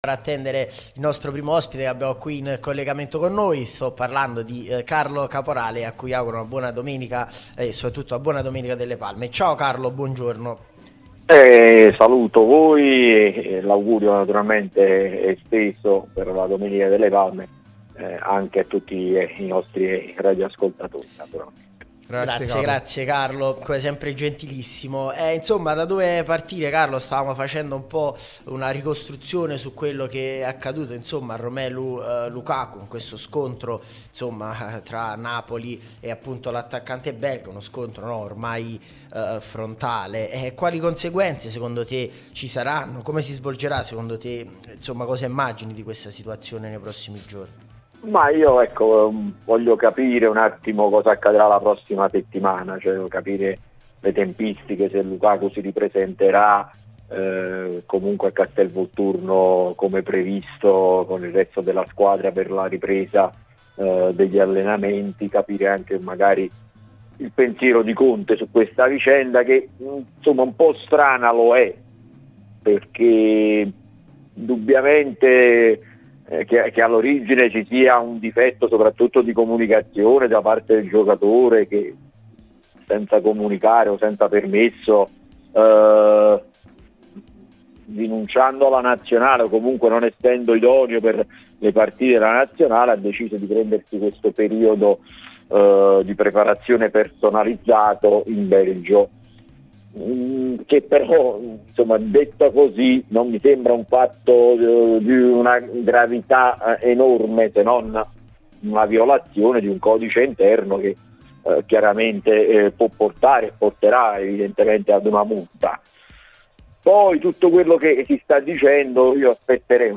l'unica radio tutta azzurra e live tutto il giorno